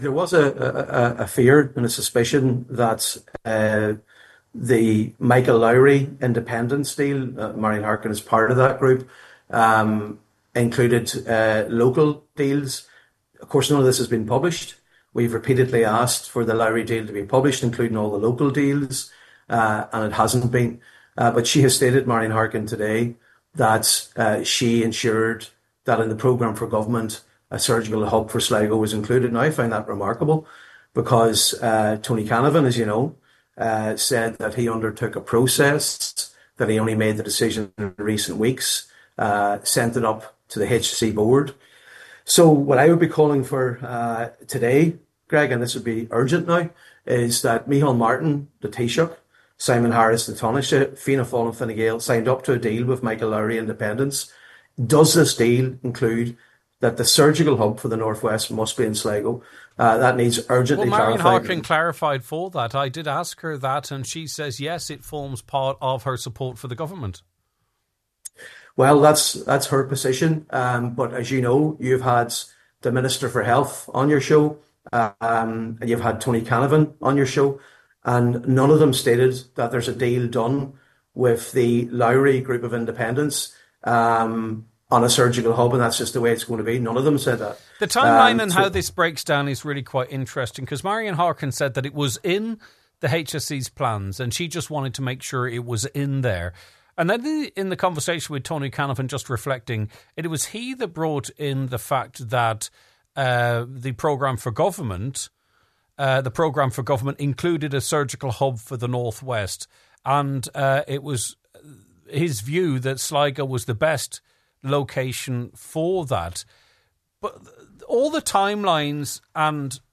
On today’s show, Donegal Deputy Padraig Mac Lochlainn said Marian Harkin’s revelation raises questions in terms of what local deals were done when the government was negotiating with Independents…….